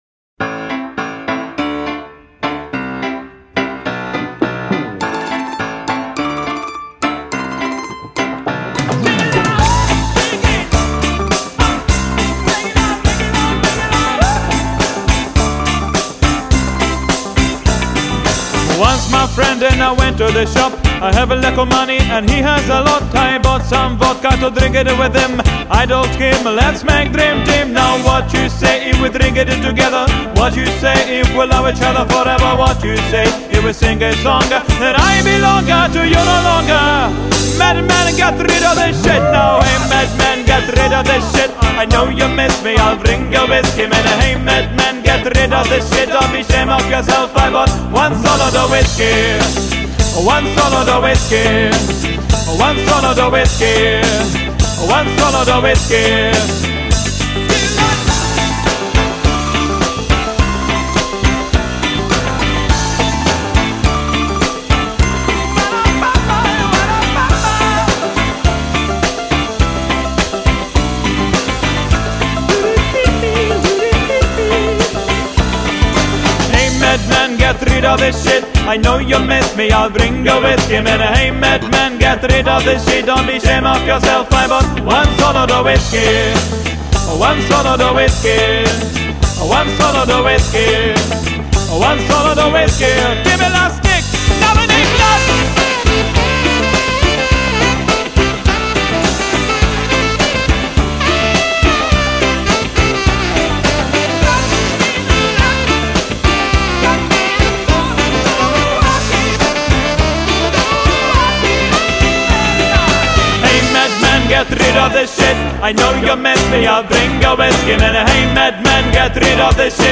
заводных песен